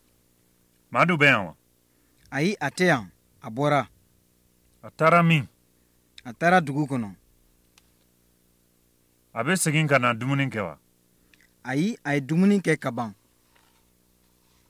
PETITS DIALOGUES POUR S’ENTRAÎNER
15-3-dialogue-p-1.mp3